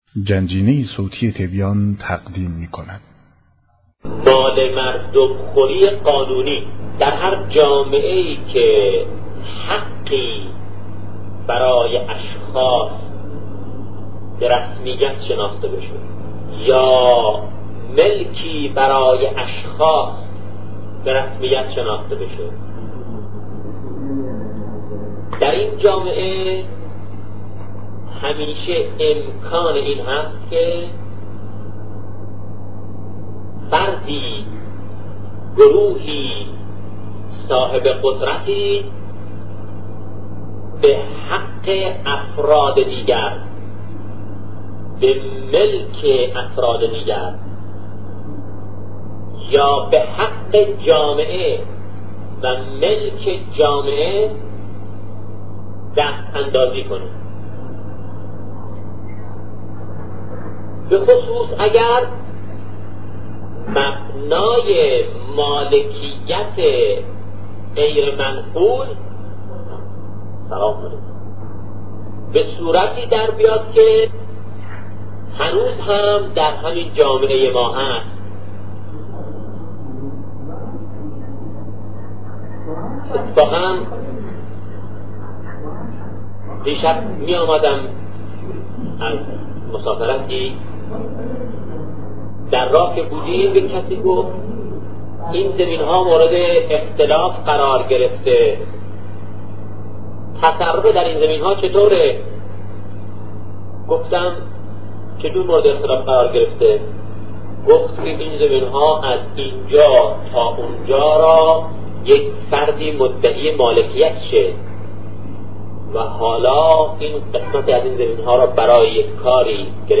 صوت شهید بهشتی- با موضوعیت ایمان و نقش آن در زندگی
بیانات بزرگان